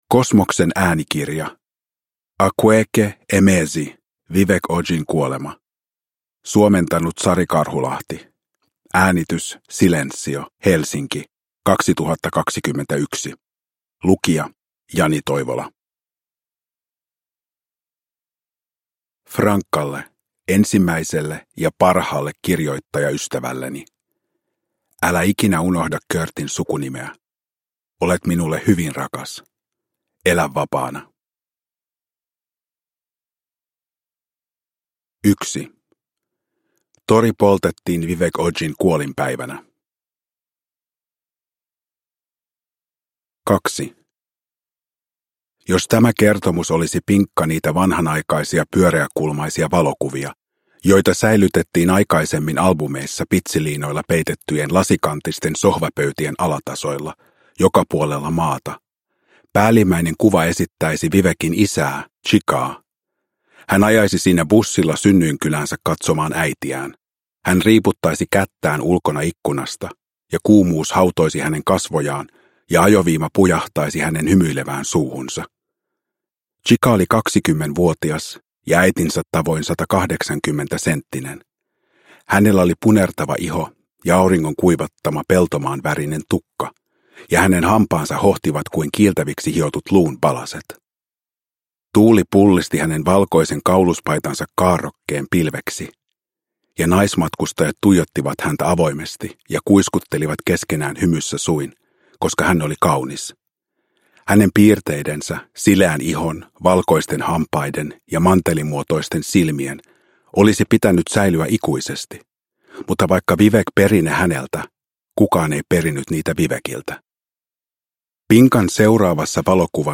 Vivek Ojin kuolema – Ljudbok
Uppläsare: Jani Toivola